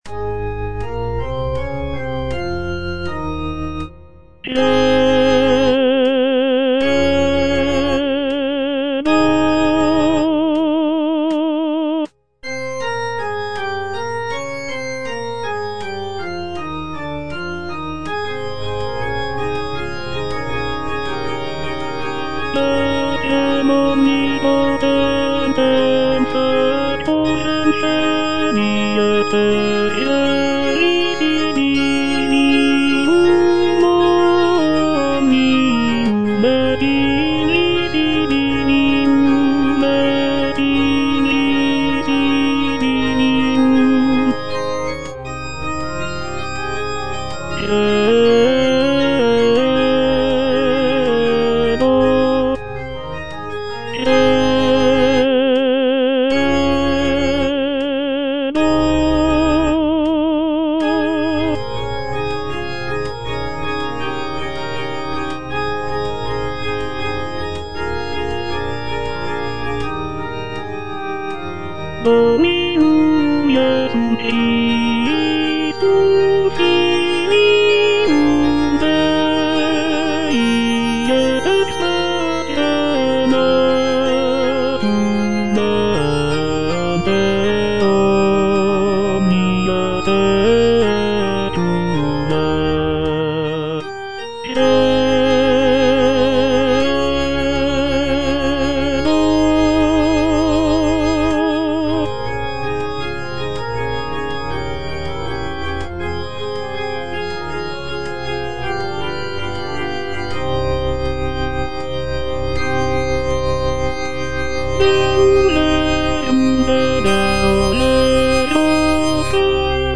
C.M. VON WEBER - MISSA SANCTA NO.1 Credo - Tenor (Voice with metronome) Ads stop: auto-stop
"Missa sancta no. 1" by Carl Maria von Weber is a sacred choral work composed in 1818.